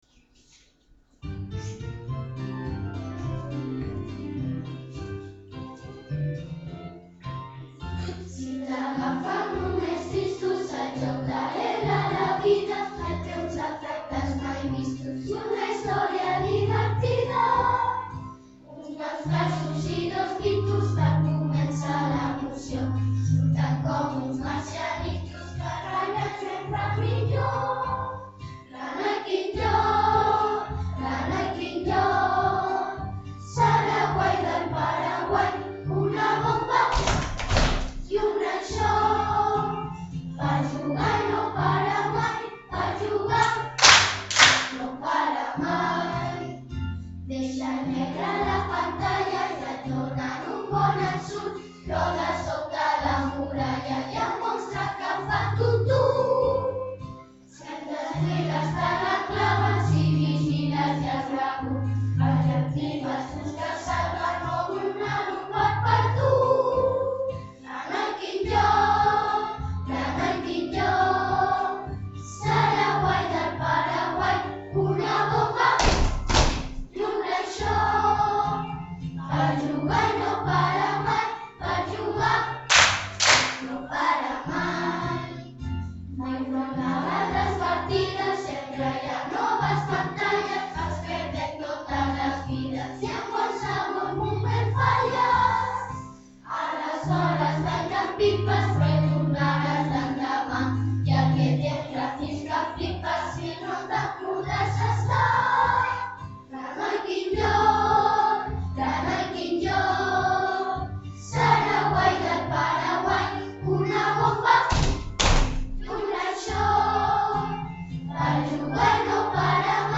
A continuació us posem totes les cançons de la cantata el Ratolí Electrònic text d’en Jesús Nieto i música d’en Ricard Gimeno que els alumnes de 4t hem enregistrat a l’escola .